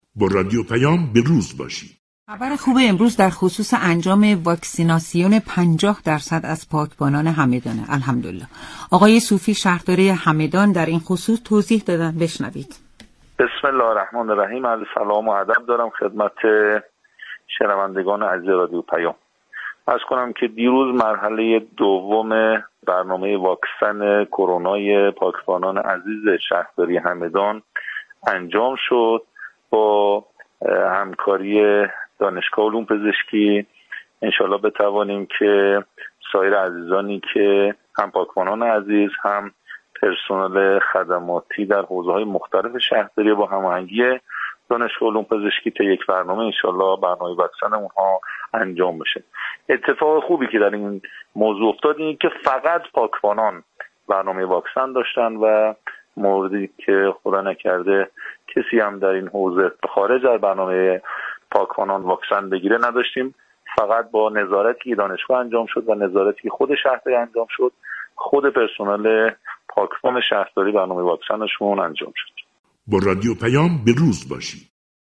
صوفی، شهردار شهر همدان، در گفتگو با رادیو پیام، از دریافت نوبت دوم واكسن كرونا نیمی از پاكبانان شهر همدان خبر داد.